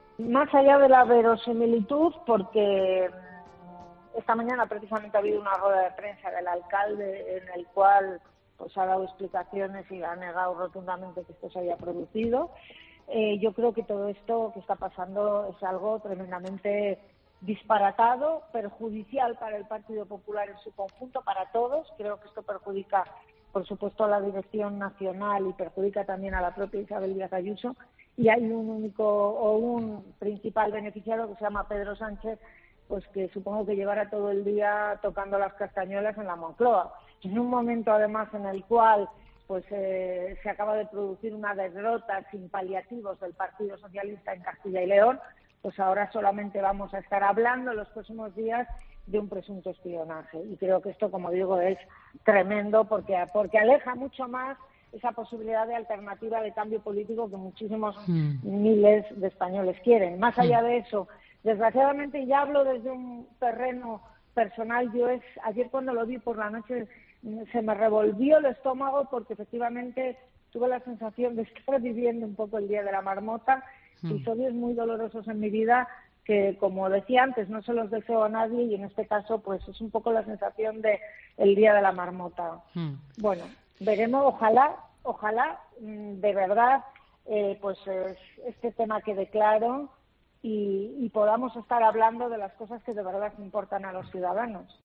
La expresidenta de la Comunidad de Madrid, Cristina Cifuentes en declaraciones al programa de COPE Valencia 'Trending COPE' ha tachado de “disparatado y perjudicial” para el PP, el supuesto espionaje a la presidenta madrileña, Isabel Díaz Ayuso, desde una empresa municipal para investigar al entorno familiar de la mandataria por contratos adjudicados para la compra de mascarillas durante la primera ola de la pandemia, según publican hoy El Mundo y El Confidencial.